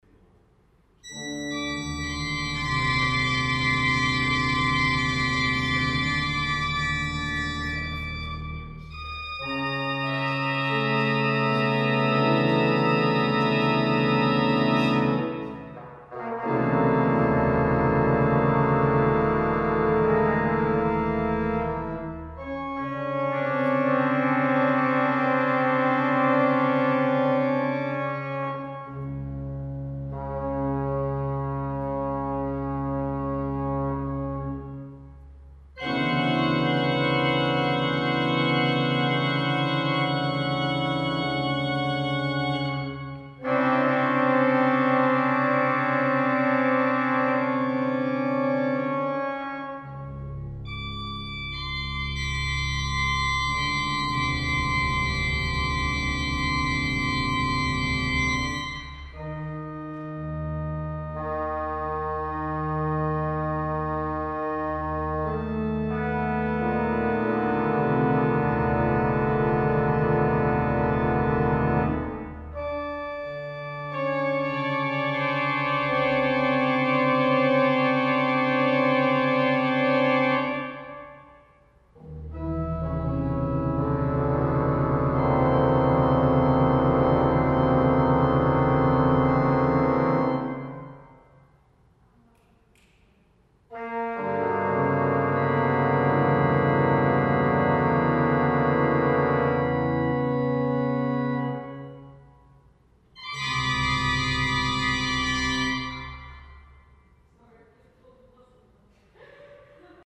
Organ Test- Extract